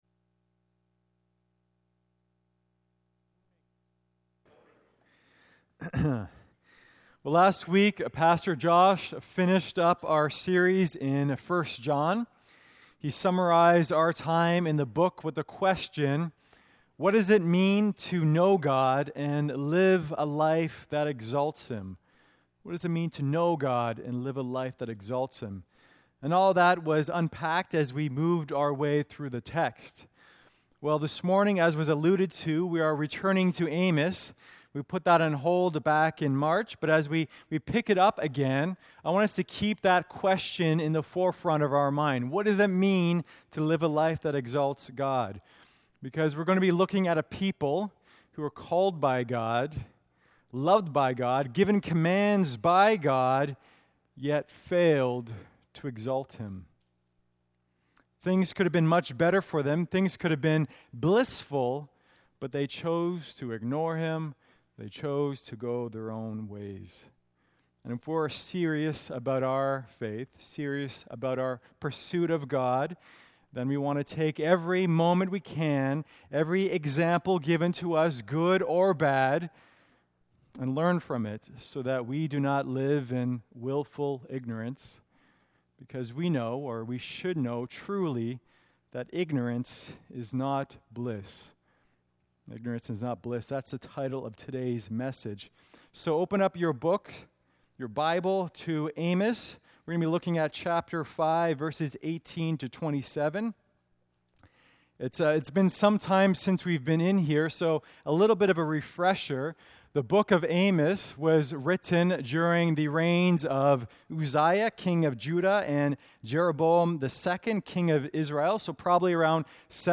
Sermon Outline:1. Ignoring the Future (18-20)2. Ignoring True Worship (21-24)3. Ignoring the Past (25-27)